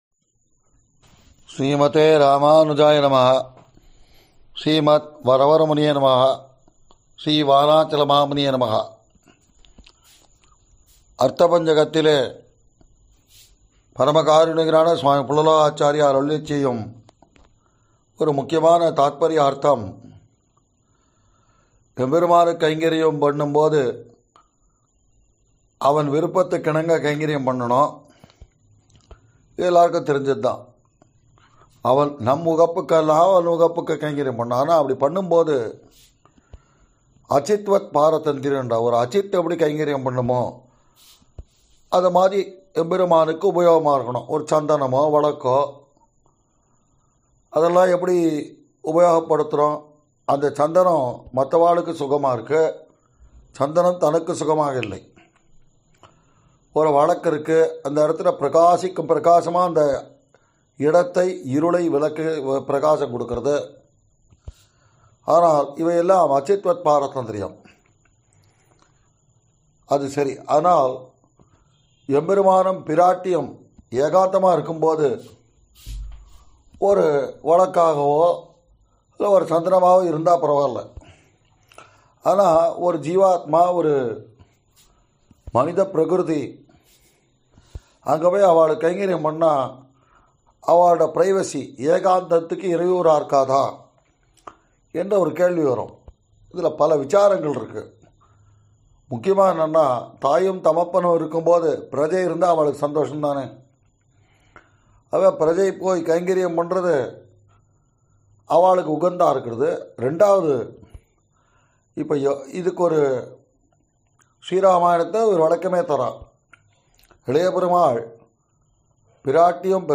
ஆறெனக்கு நின் பாதமே சரண் குழுமத்தனரின் சுபக்ருத் ௵, மார்கழி ௴ உபன்யாசம்